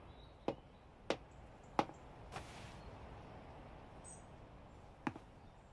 State Alchemist's Footsteps_2.wav